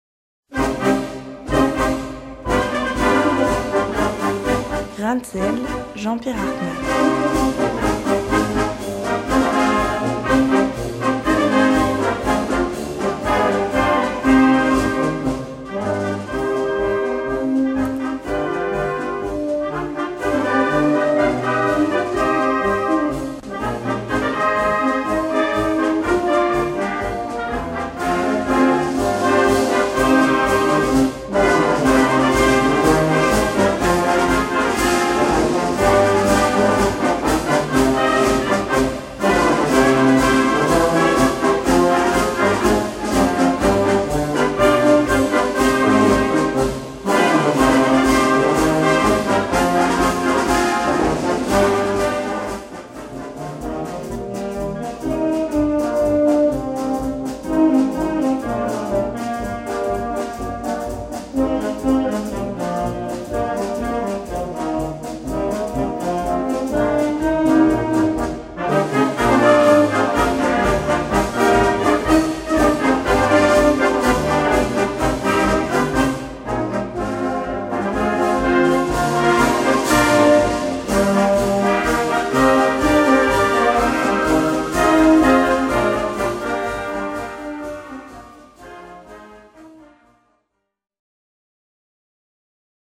Gattung: Marsch
2:21 Minuten Besetzung: Blasorchester Zu hören auf